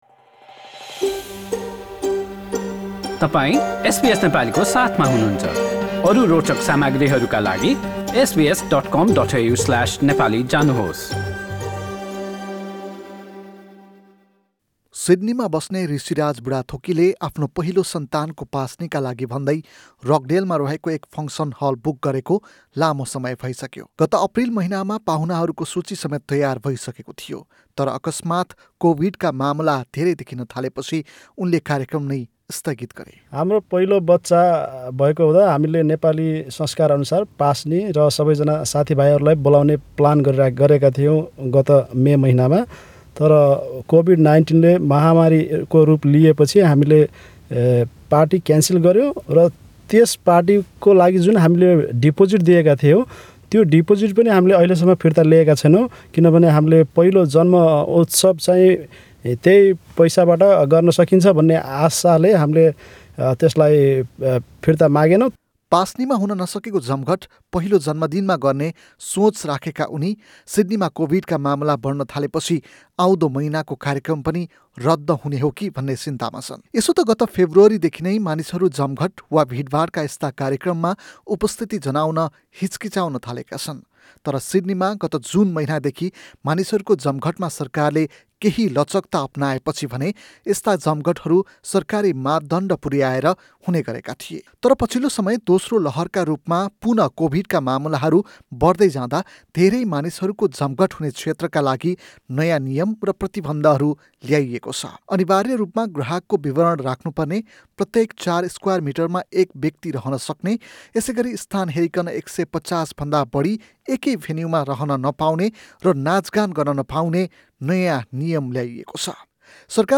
रिपोर्ट सुन्न माथिको मिडिया प्लेयरमा थिच्नुहोस् तपाईंले एसबीएस नेपालीका विशेष सामाग्रीहरू स्पटीफाइ , एप्पल पोडकाष्ट्स र गुगल पोडकाष्ट्स मार्फत पनि सुन्न सक्नुहुनेछ।